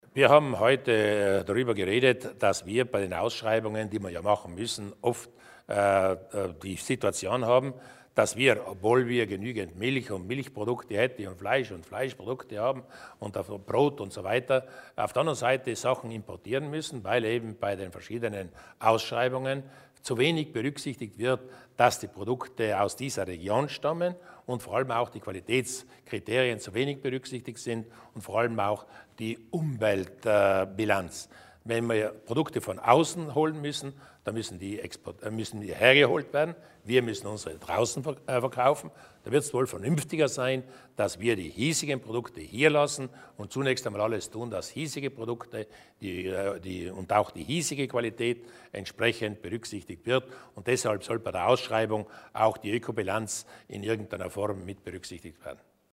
Landeshauptmann Durnwalder erläutert die Vorhaben zur Stärkung lokaler Produkte